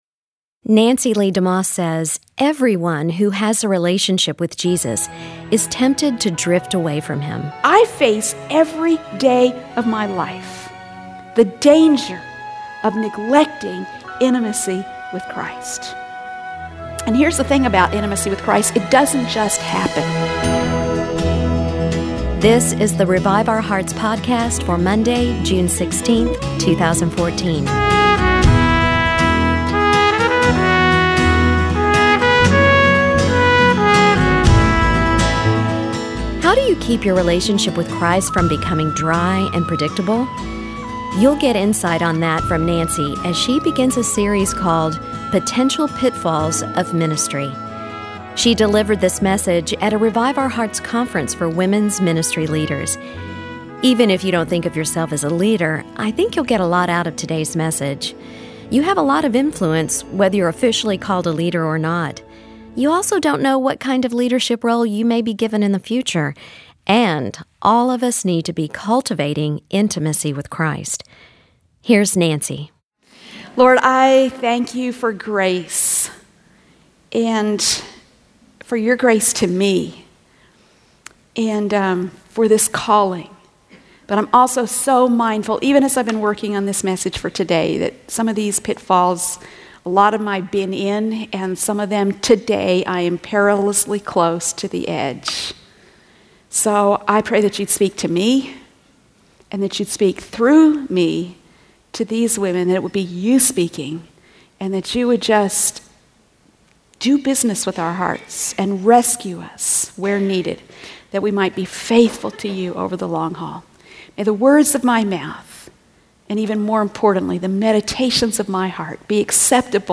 She delivered this message at a Revive Our Hearts conference for women’s ministry leaders.